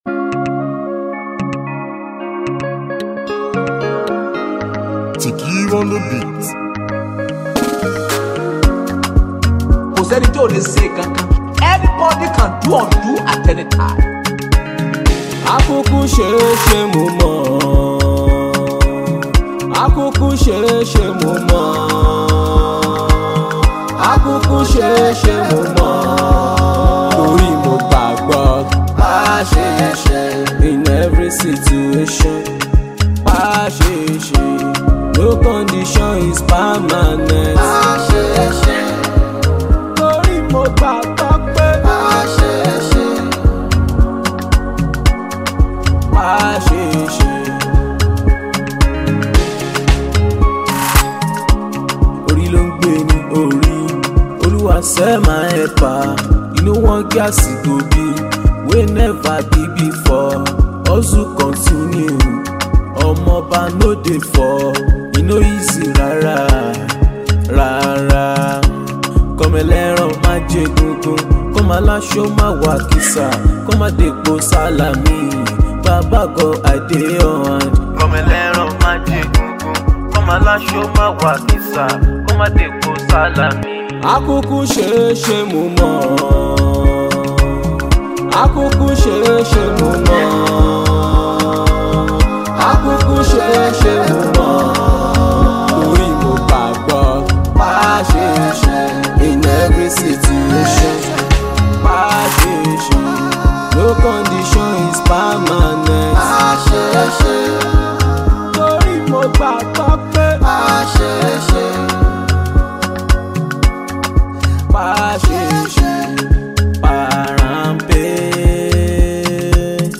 comes with lovely and infectious beats